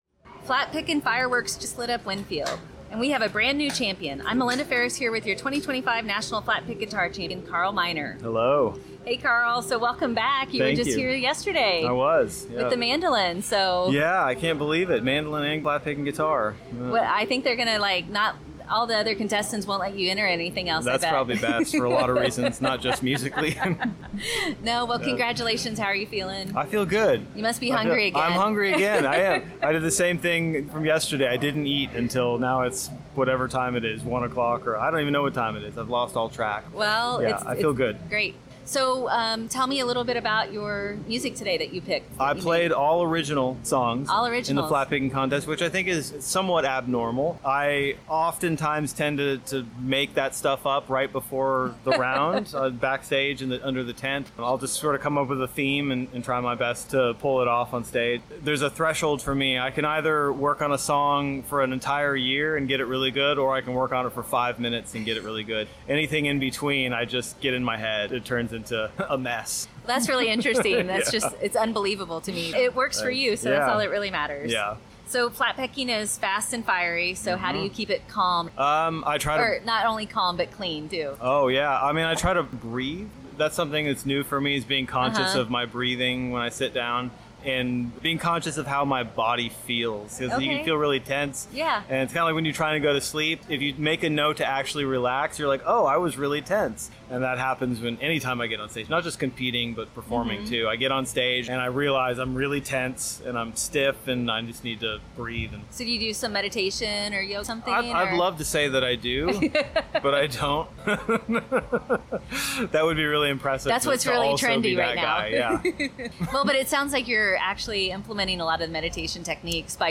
Campground Radio Interview.